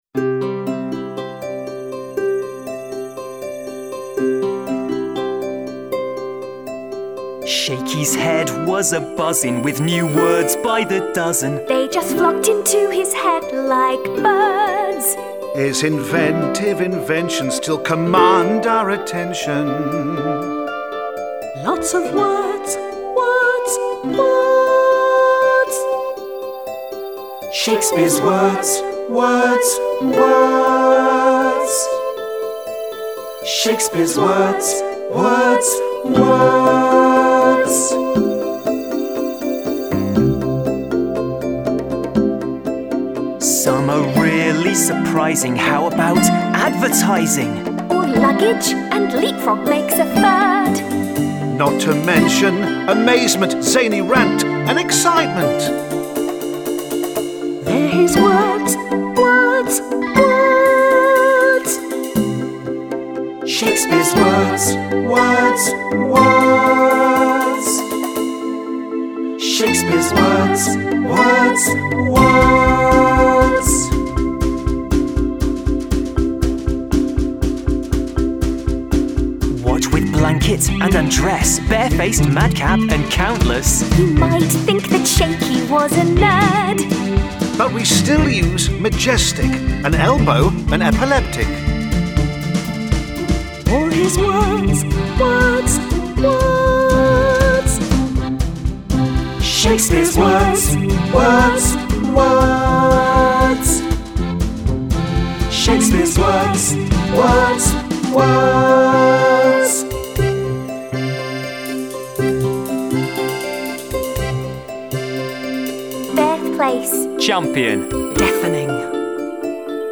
Full vocal.